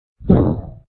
Boss_COG_VO_grunt.ogg